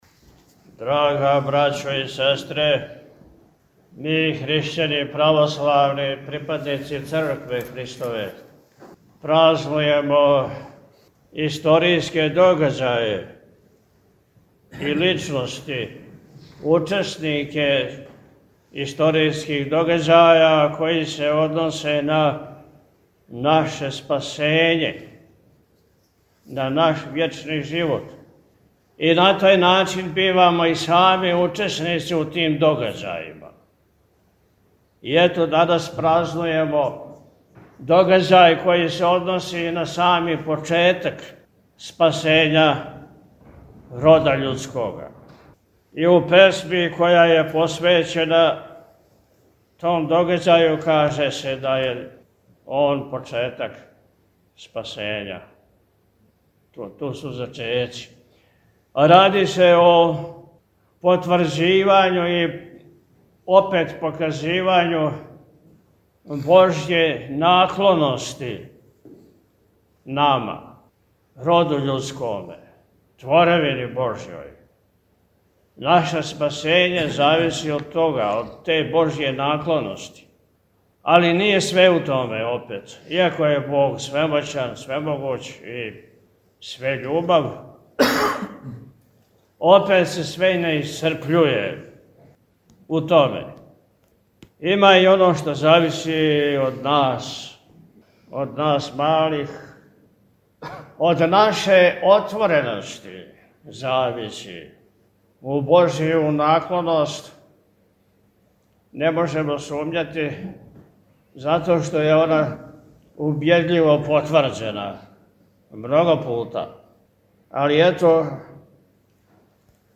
Након отпуста Високопреосвећени је, честитајући празник и учешће у Божанској Литургији свима сабранима, у пастирској беседи рекао: